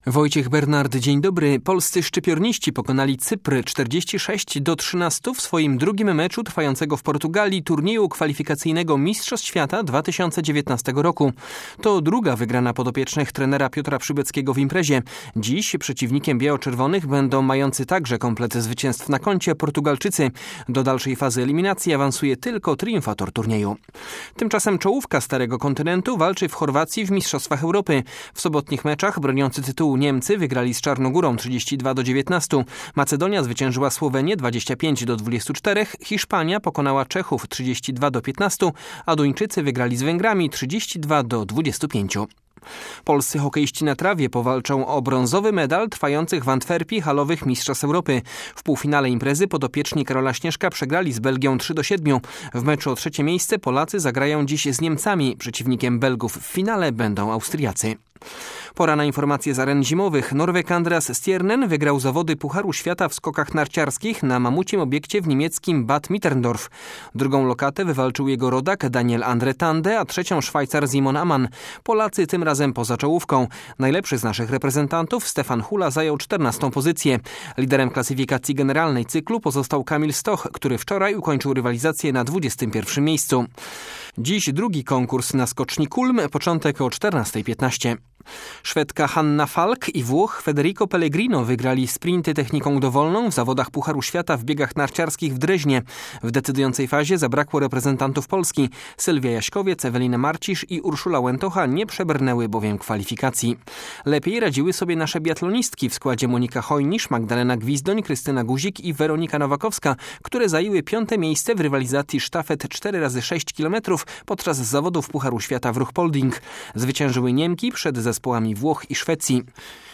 14.01 serwis sportowy godz. 9:05
W porannym serwisie sportowym między innymi o kolejnym zwycięstwie polskich szczypiornistów w turnieju kwalifikacyjnym do Mistrzostw Świata oraz o medalowych szansach naszych laskarzy podczas Halowych Mistrzostw Europy. Poza tym sporo wyników ligowych wielkopolskich drużyn i wypowiedź trenera Nenada Bjelicy przed pierwszym zimowym sparingiem piłkarzy Lecha Poznań.